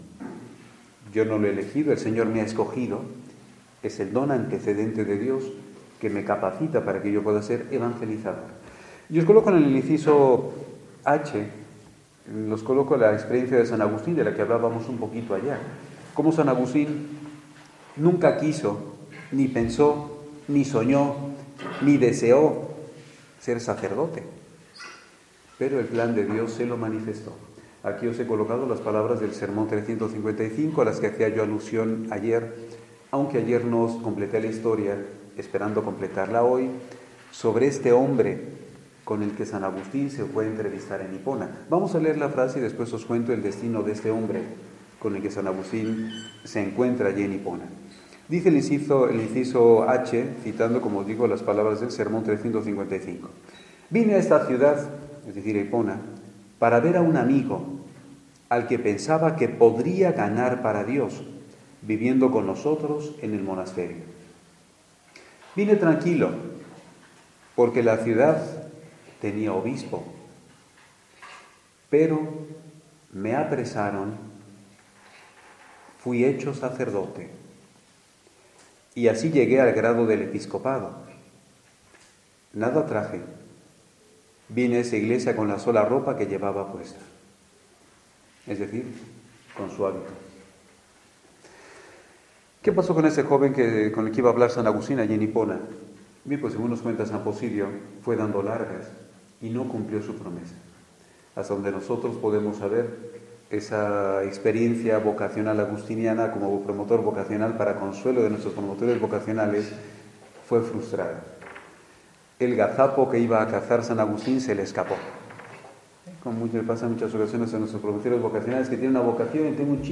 Ejercicios Espirituales